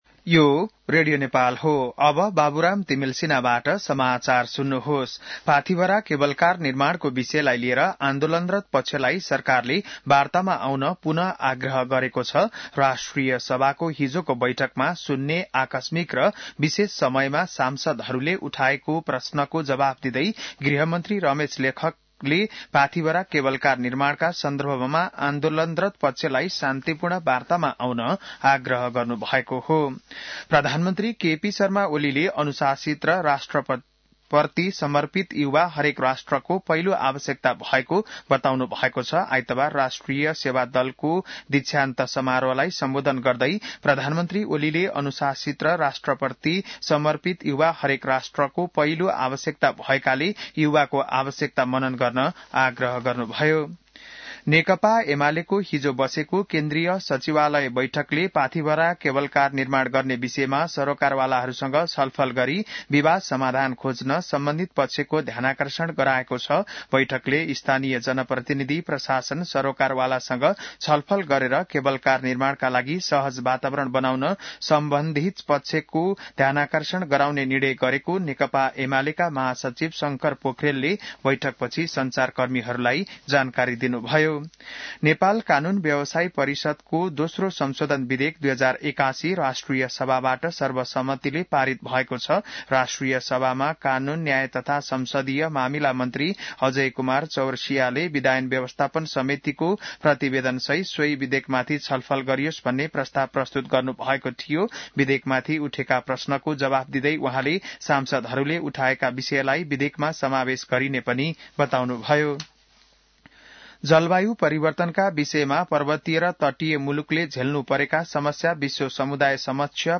बिहान १० बजेको नेपाली समाचार : २० फागुन , २०८१